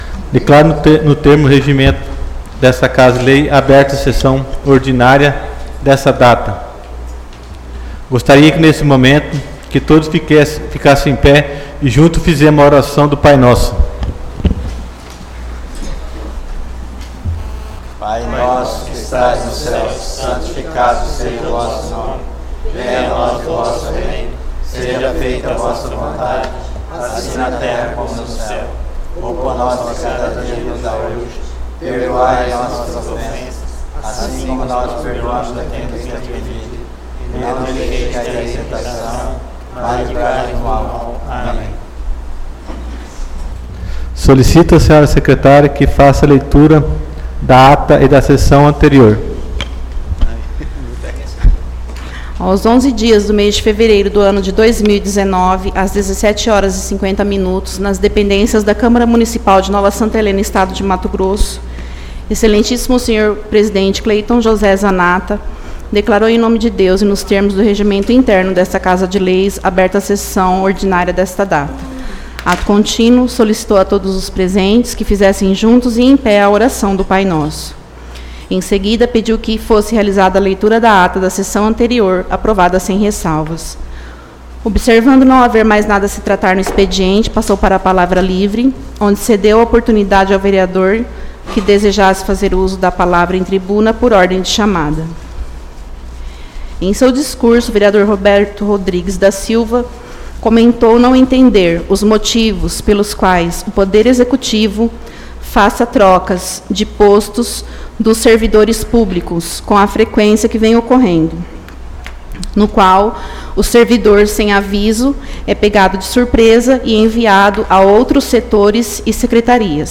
Audio Sessão Ordinária 18/02/2019